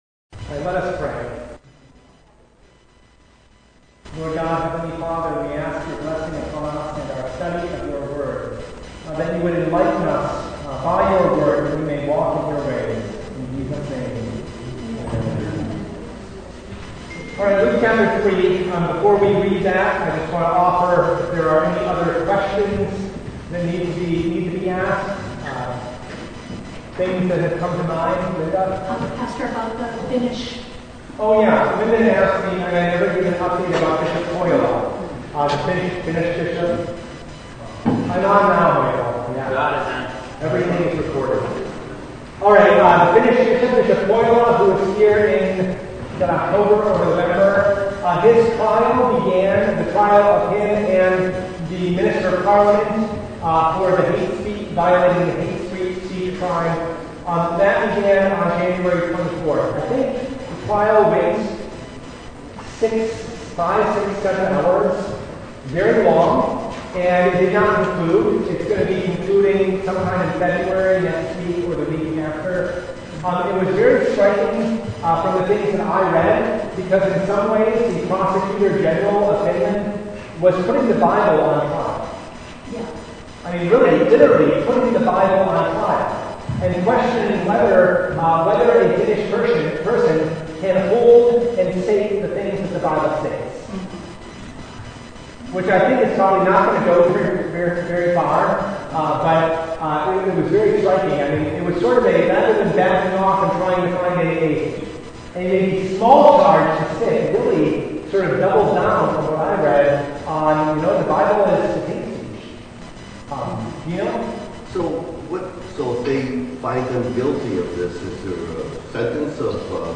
Passage: Luke 3:10-22 Service Type: Bible Study